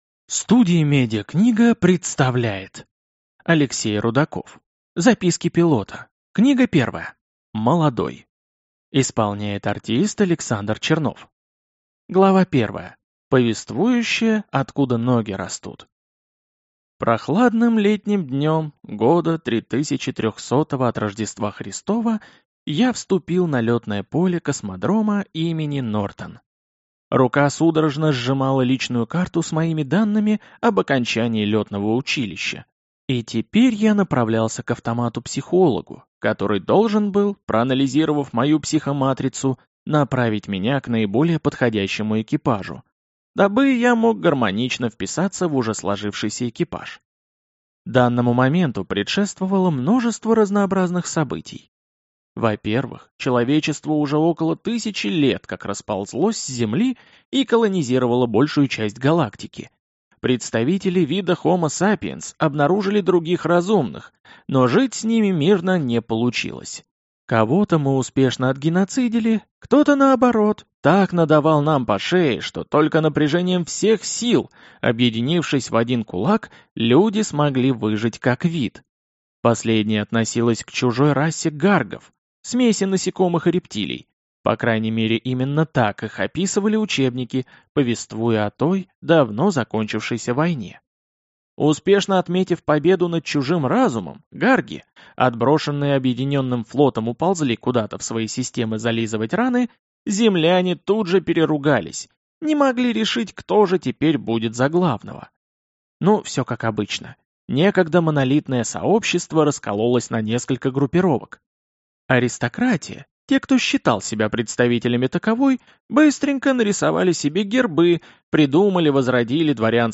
Аудиокнига Молодой | Библиотека аудиокниг